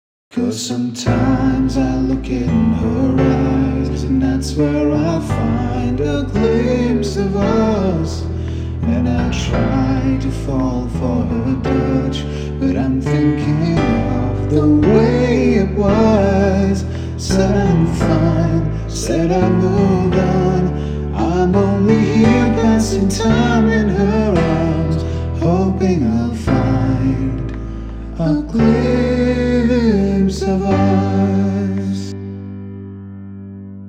Music / Pop
vocal cover